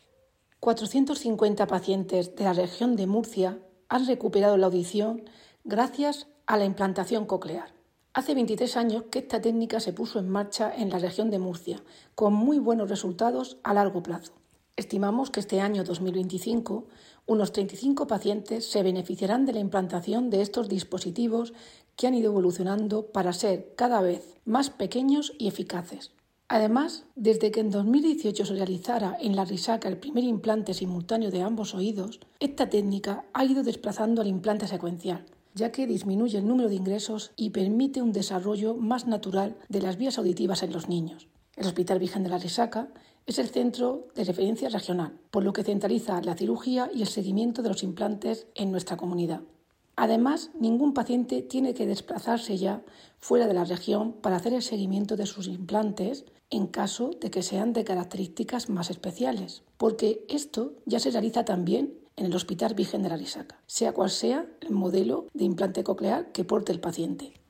Declaraciones de la directora general de Atención Hospitalaria, Irene Marín, sobre la evolución de los implantes cocleares en la Región de Murcia.